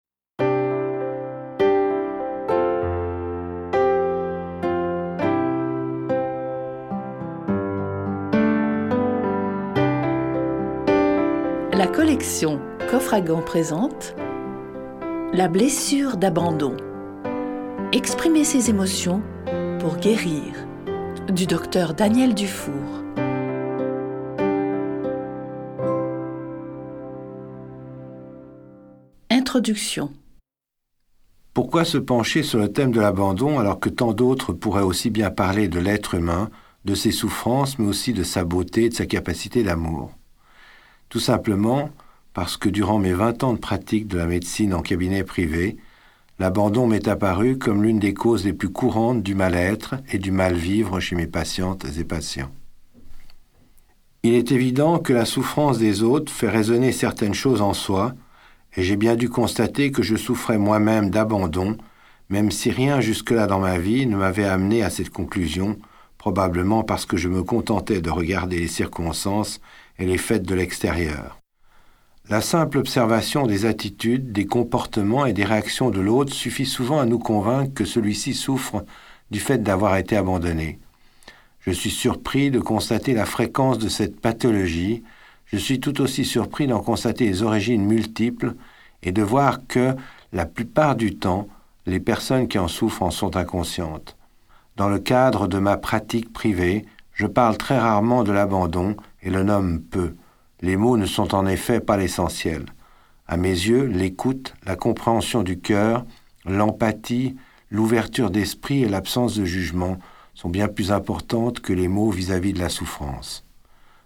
Dans ce cas, ce livre audio est fait pour vous. Vous y trouverez des histoires de cas, des analyses, des conseils, tout ce qui peut vous aider à guérir pour aimer mieux, c’est-à-dire aimer dans la sérénité, sans crainte d’être abandonné.